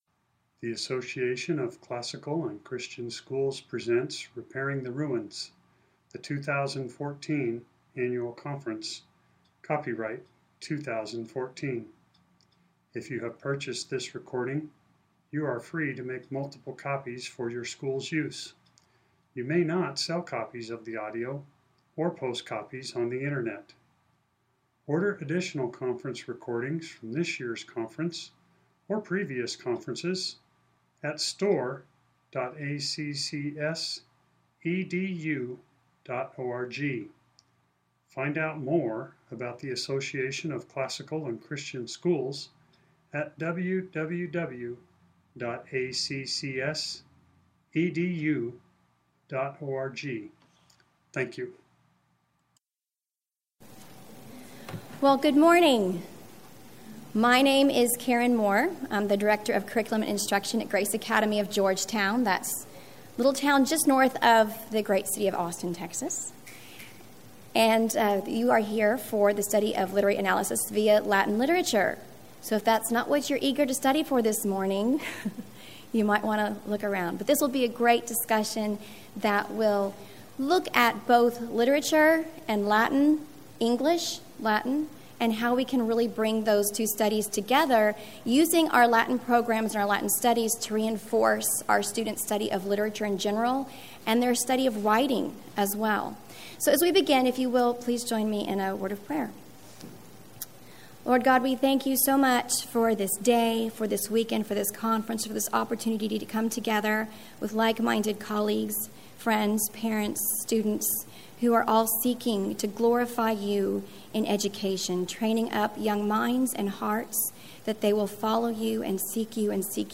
2014 Workshop Talk | 0:57:36 | All Grade Levels, Latin, Greek & Language, Literature
The Association of Classical & Christian Schools presents Repairing the Ruins, the ACCS annual conference, copyright ACCS.